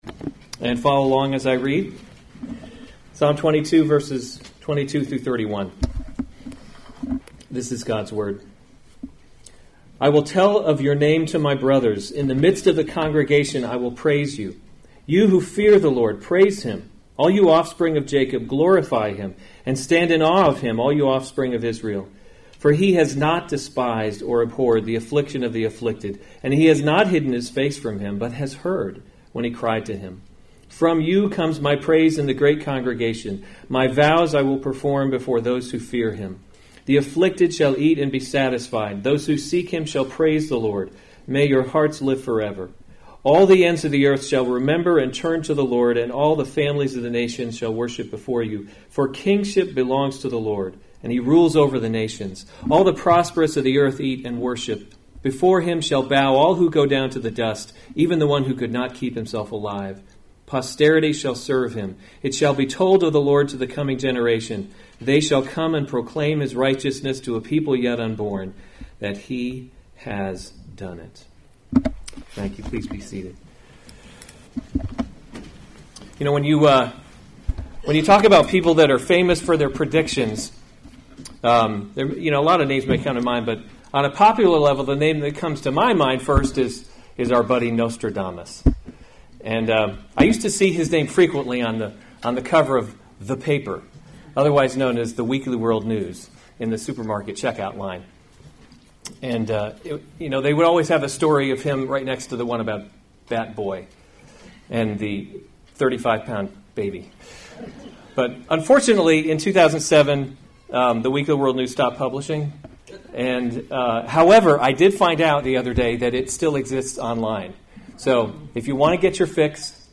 March 31, 2018 Special Services series Easter Service Save/Download this sermon Psalm 22:22-31 Other sermons from Psalm 22 I will tell of your name to my brothers; in the midst of […]